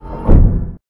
dockinggearretract.ogg